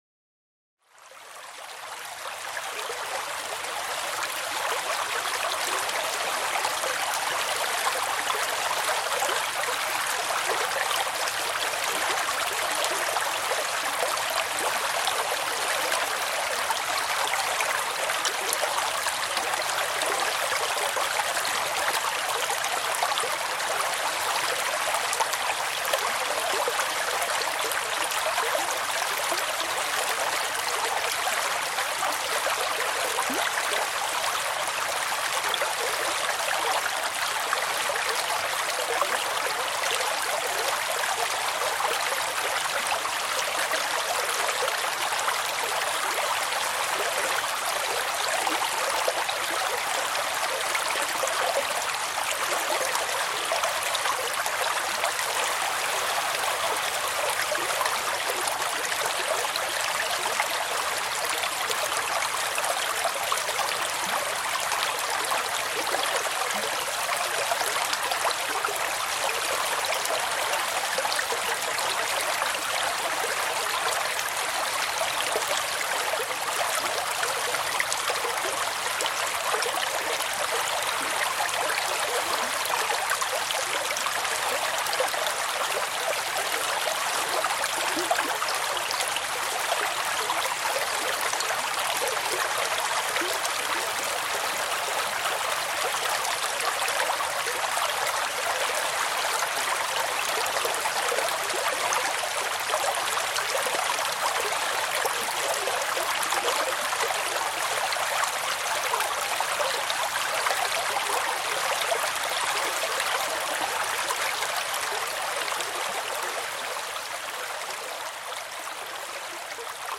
Heilquelle Sanftes Rauschen & Vogelgesang | Mystische Entspannung